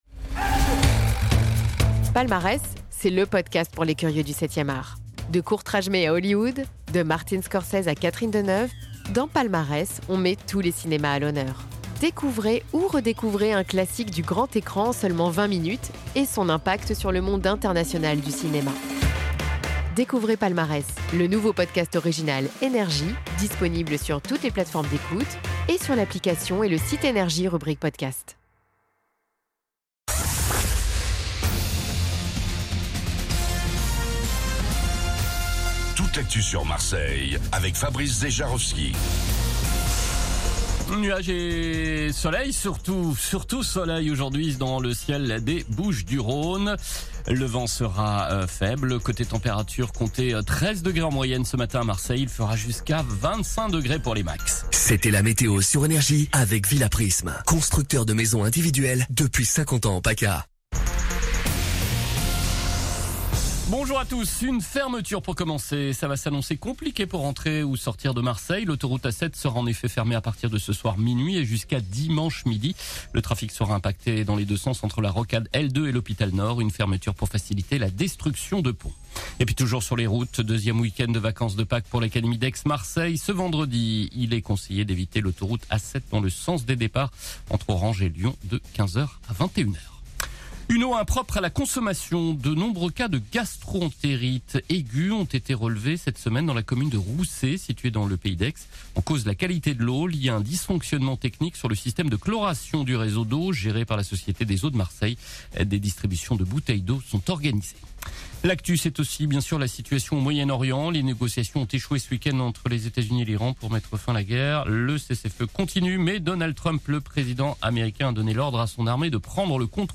Réécoutez vos INFOS, METEO et TRAFIC de NRJ MARSEILLE du vendredi 17 avril 2026 à 06h30